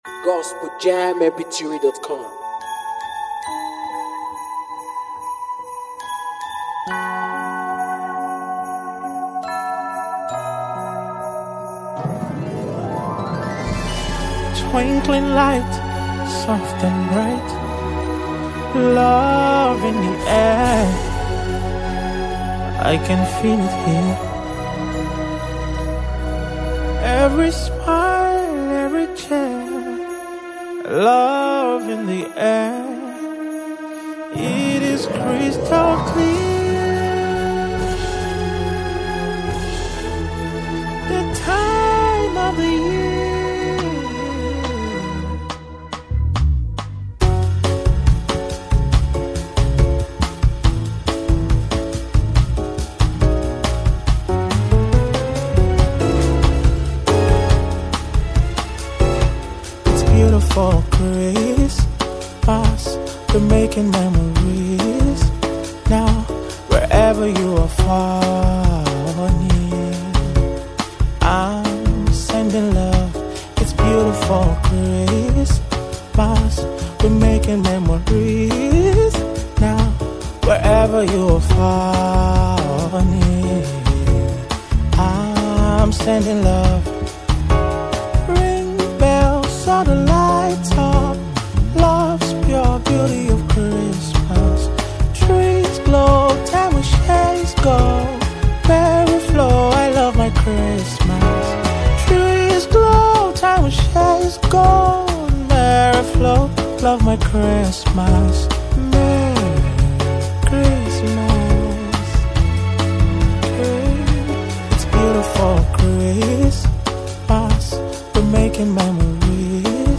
is a warm, joyful celebration of the holiday season
With his smooth vocals and heartfelt delivery